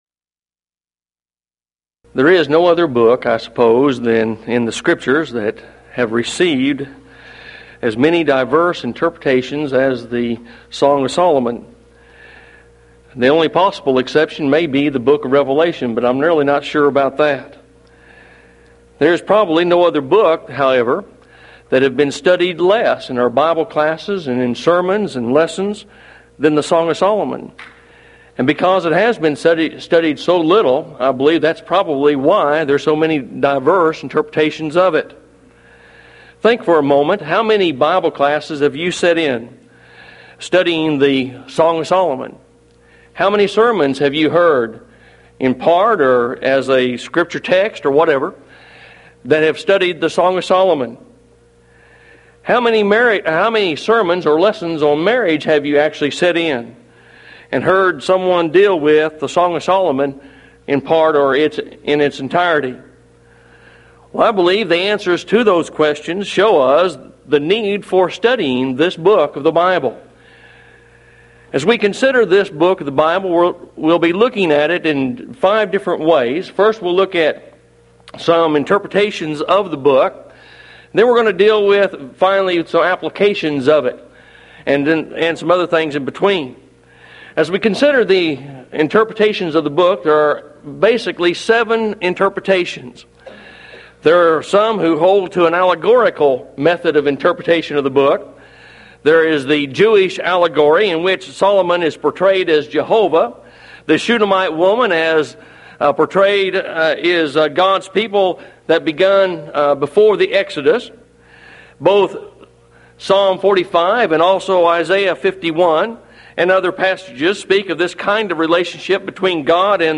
Event: 1993 Mid-West Lectures
lecture